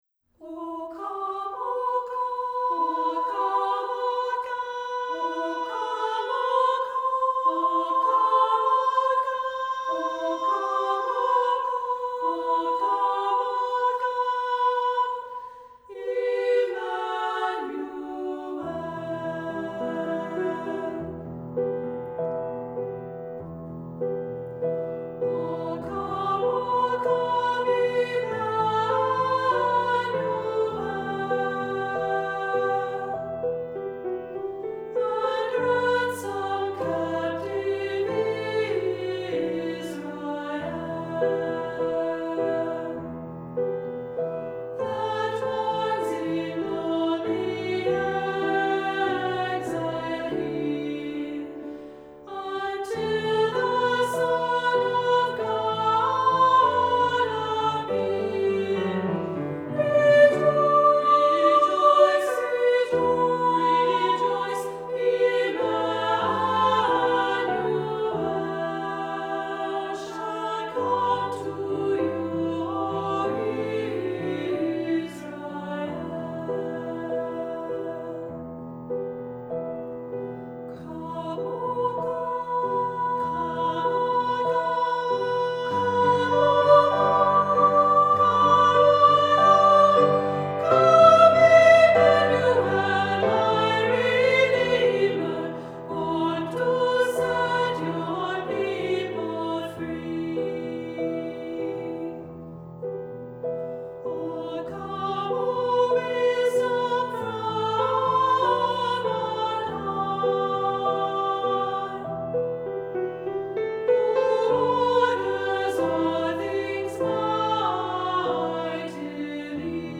Voicing: SA